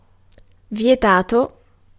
Ääntäminen
IPA : /fɝˈbɪdən/